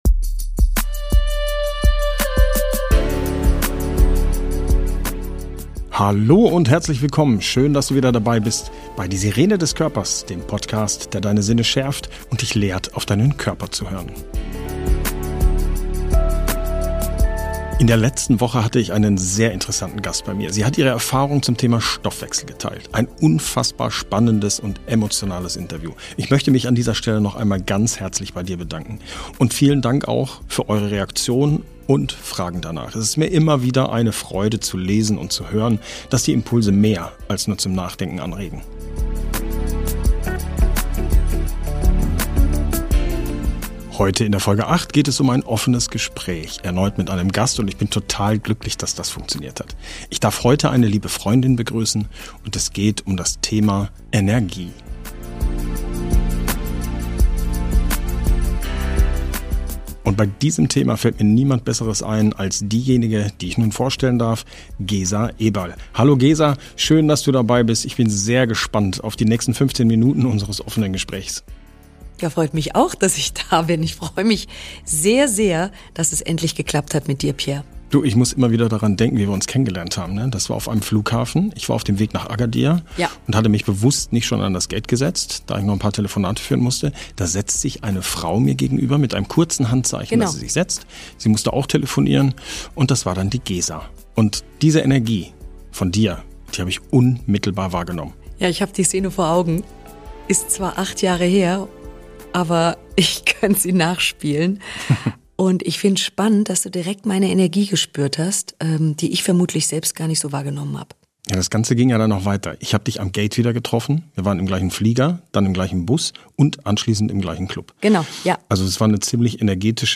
In dieser Podcast-Folge erwartet dich ein offenes Gespräch mit einem Gast, bei dem wir uns intensiv mit dem faszinierenden Thema der Energie auseinandersetzen.